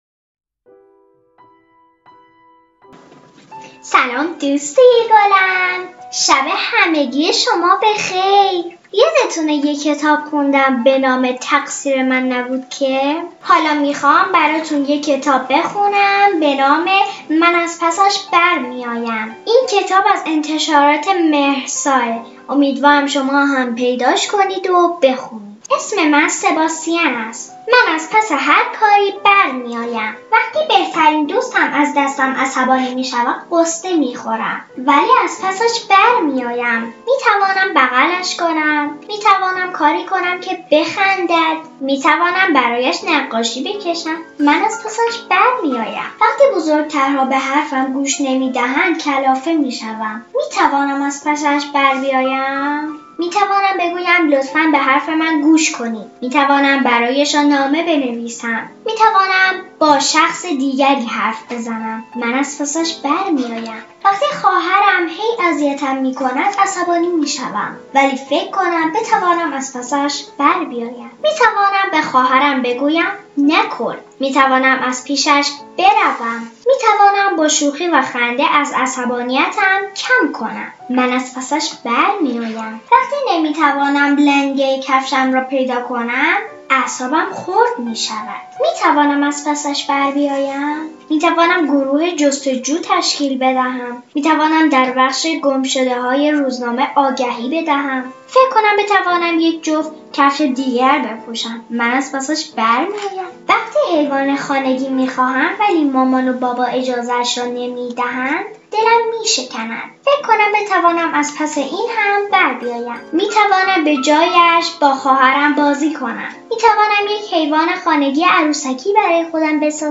• قصه کودکان داستان کودکان قصه صوتی